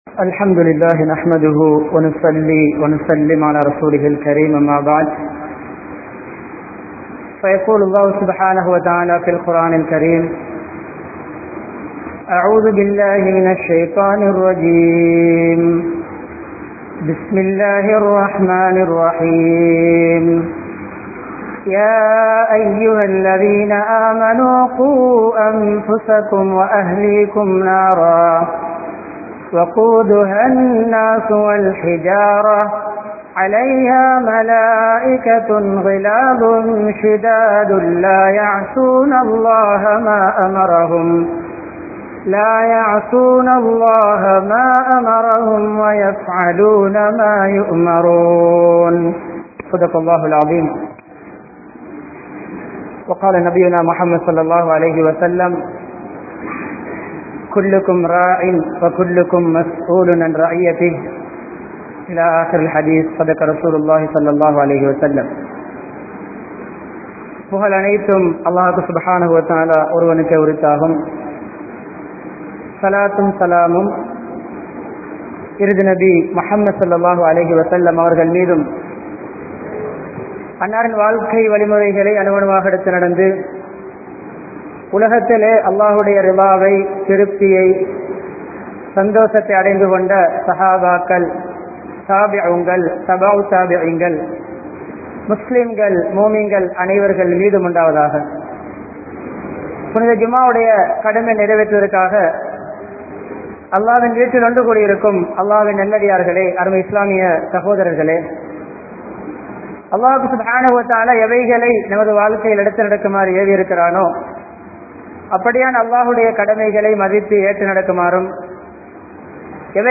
Seer Thiruththa Pada Veandiya Moovar (சீர்திருத்தப்பட வேண்டிய மூவர்) | Audio Bayans | All Ceylon Muslim Youth Community | Addalaichenai
Salihath Jumua Masjidh